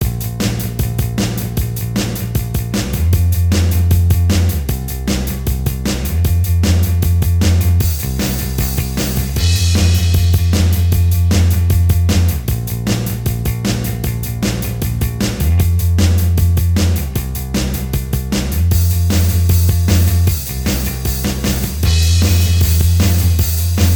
Minus Guitars Rock 3:52 Buy £1.50